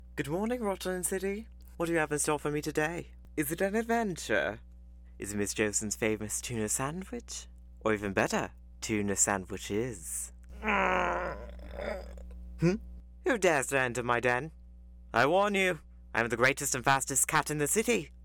Street, Intelligent
North American (General), North American (US New York, New Jersey, Bronx, Brooklyn), North American (US South), North American (Mid-Atlantic), British (England - East Midlands)